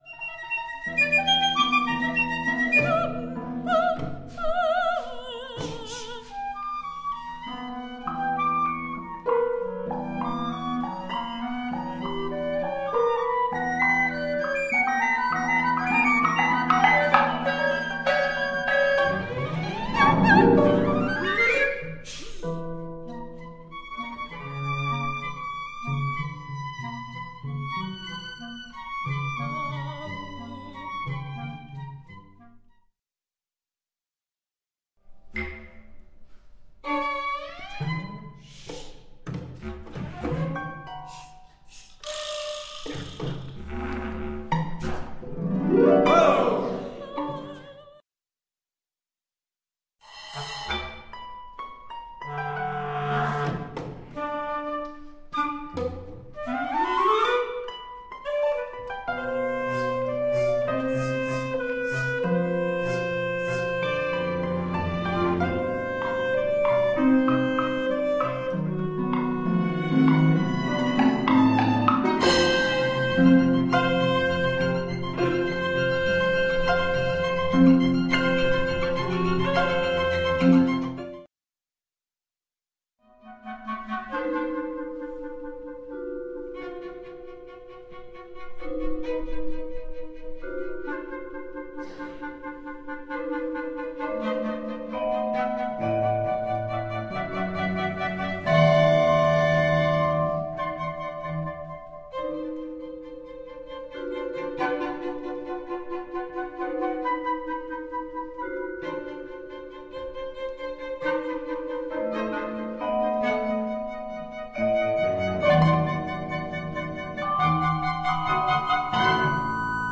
for mixed chamber ensemble (14')
The score calls for flute/piccolo, clarinet in B-flat/bass clarinet, percussion, harp, soprano, violin, and cello.